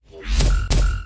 Play, download and share BASSTAB2 original sound button!!!!
bassstab2.mp3